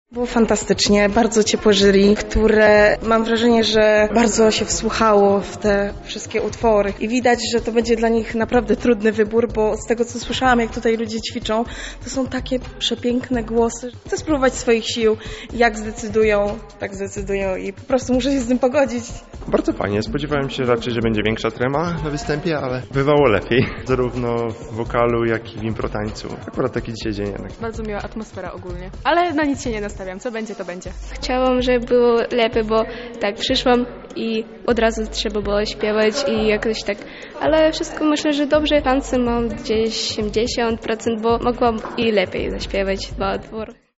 Uczestnicy podzielili się swoimi spostrzeżeniami.
relka musical